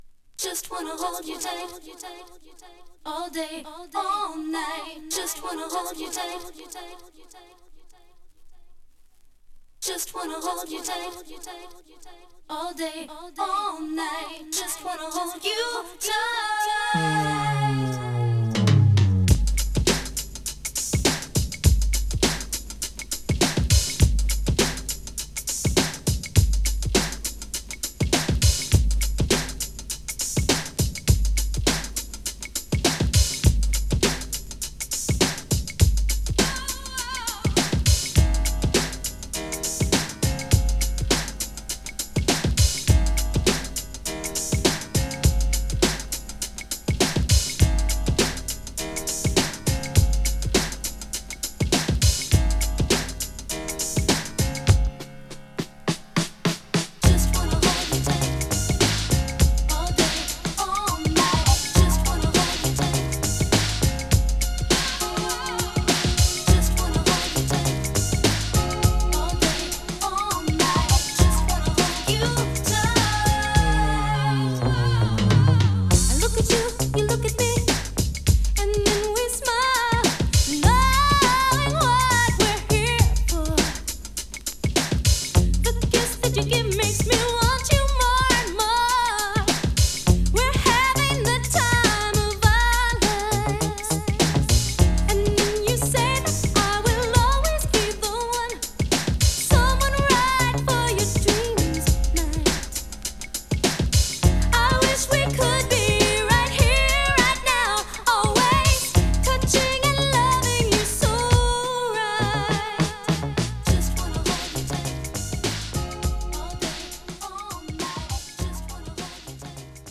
90年代前半のクラシック!!グラウンドビートをバックに印象的なシンセリフが瑞々しく弾ける大名曲。